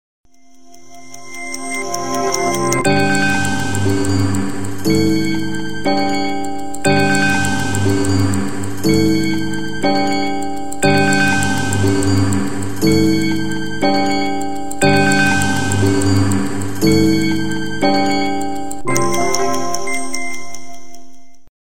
• Качество: 320, Stereo
тиканье часов
перезвон часов
Ход, тиканье, перезвон часов.